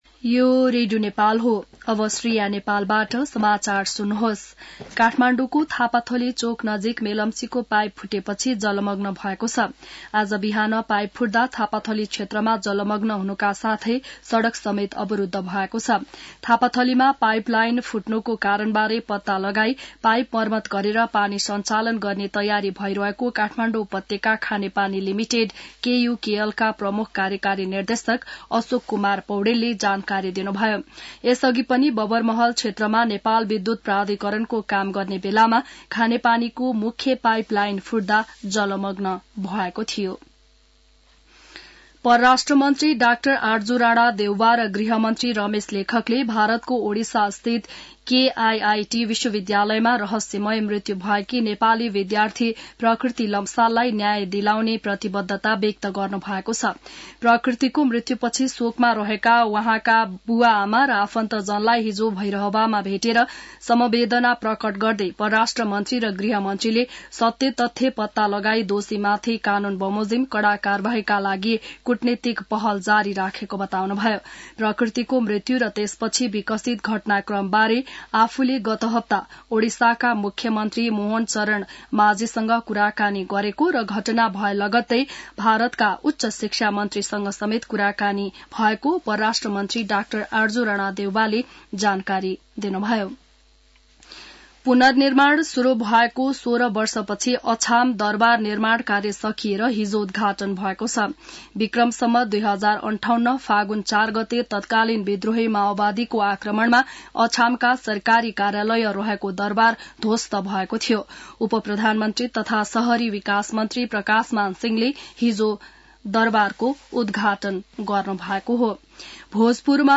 बिहान १० बजेको नेपाली समाचार : २१ फागुन , २०८१